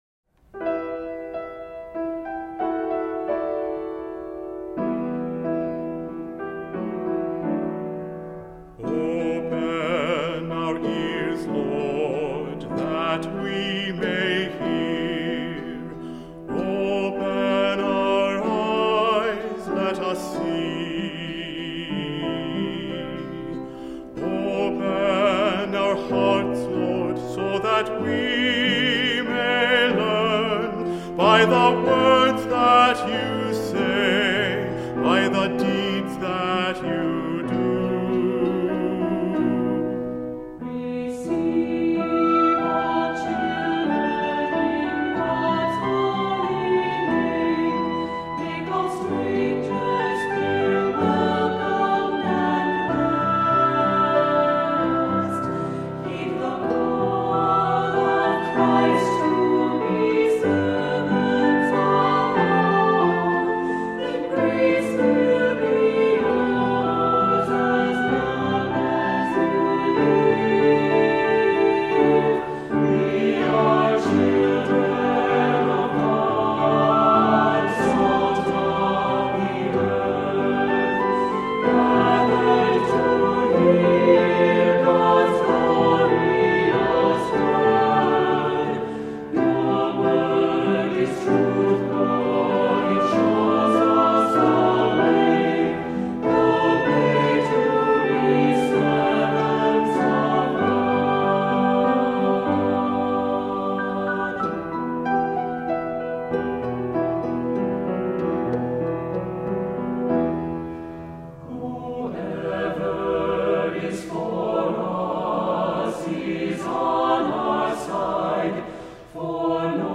Voicing: Assembly, cantor